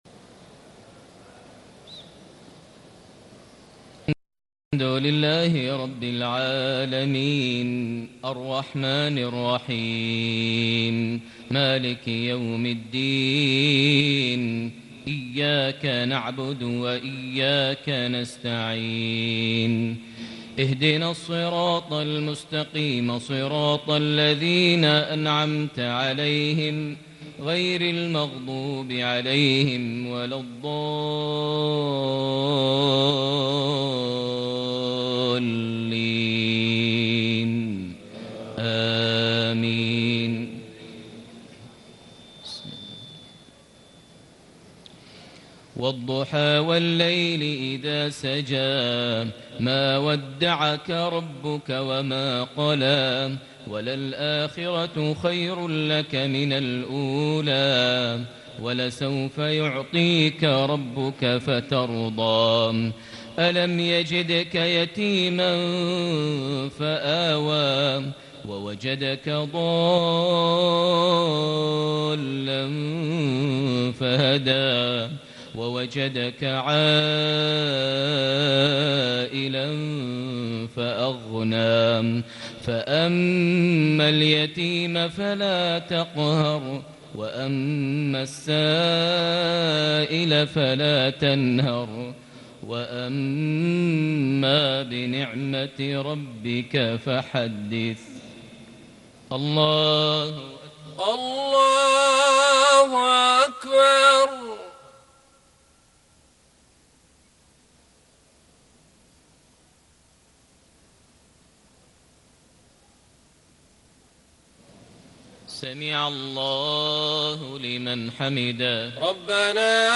صلاة المغرب ١٠ ذي الحجة ١٤٣٨هـ سورتي الضحى / الكوثر > 1438 هـ > الفروض - تلاوات ماهر المعيقلي